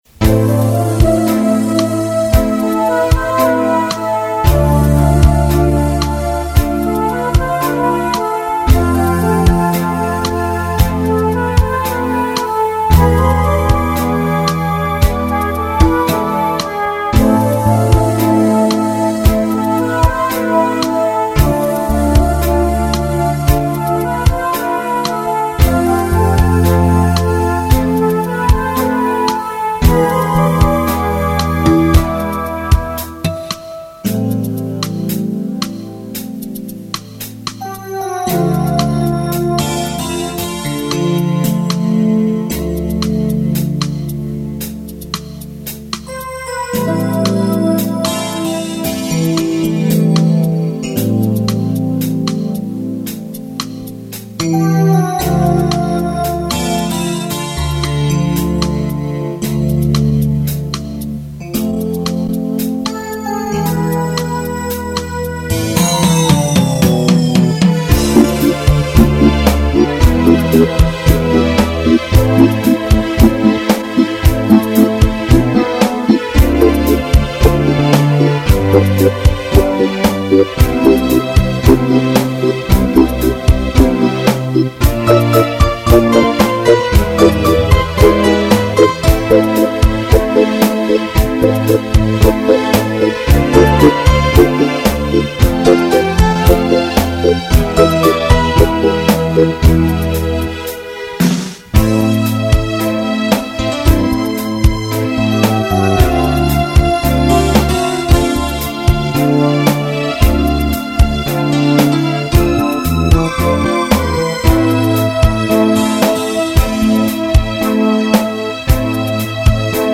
(минусовка)